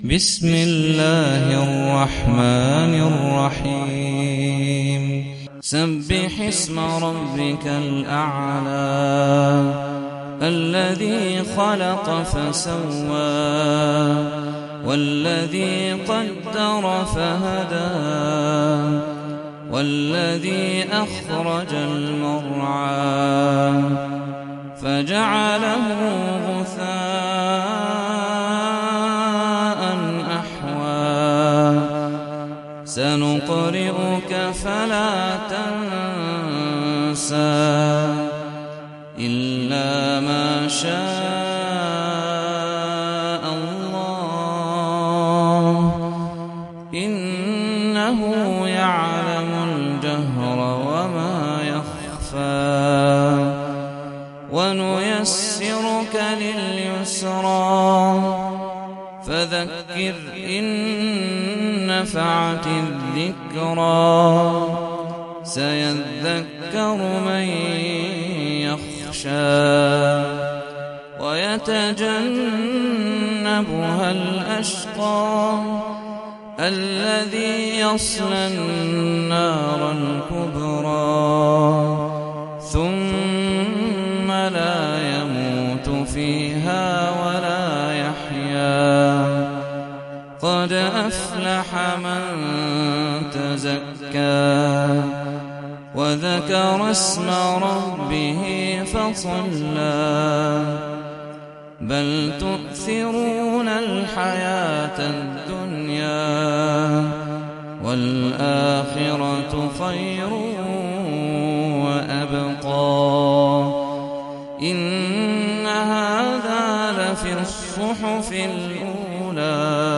سورة الأعلى - صلاة التراويح 1446 هـ (برواية حفص عن عاصم)
جودة عالية